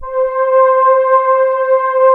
MKS PAD C6.wav